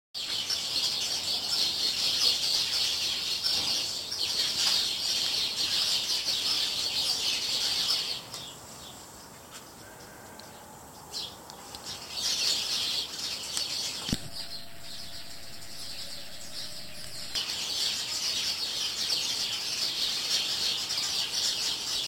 Pour finir, le néflier est squatté toutes les nuits par une colonie de moineaux ibériques, entre 50 et 100 à vue de nez. Je ne sais pas si ça passe l'hiver ici, en attendant c'est un sacré potin au coucher et levé du soleil, rarement vu un piaf aussi bruyant, pire que les hirondelles.